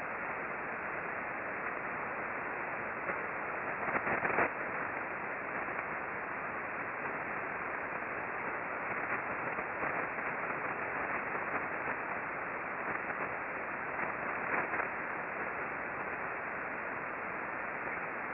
We observed mostly S-bursts throughout the pass. There also was considerable interference from sweepers and other repetitive signals.
We suffer considerable radio frequency interference (RFI) and the received emissions from Jupiter are only a few dB above the background RFI.